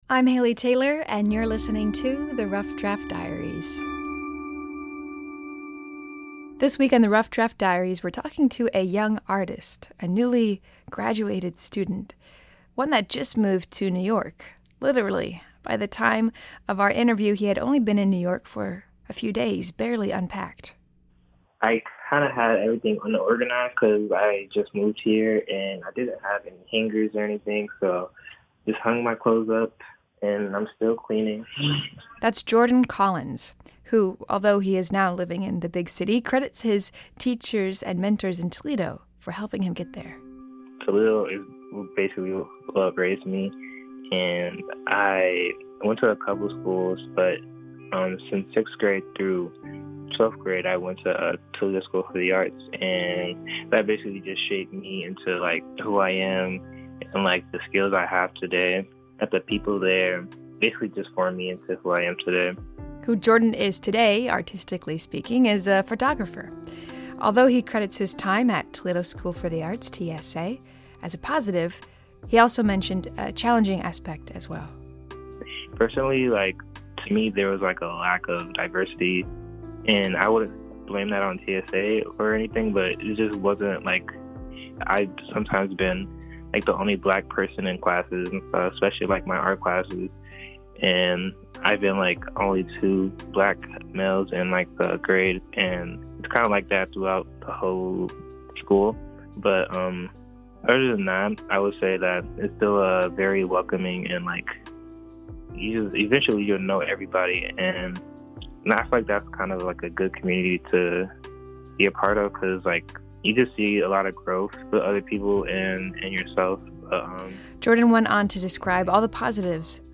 Today on The Rough Draft Diaries, we're talking to a young artist, one that has just recently moved to New York City.